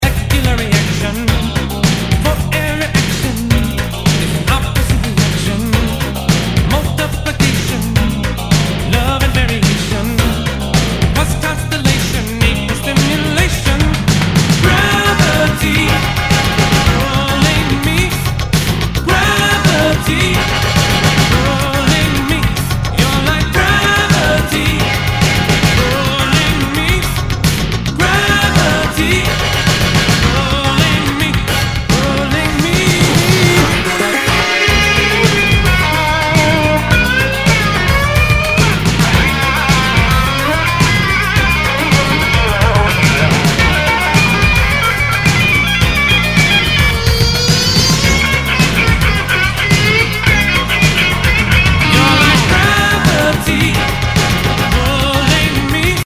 ROCK/POPS/INDIE
シンセ・ポップ！！
全体にチリノイズが入ります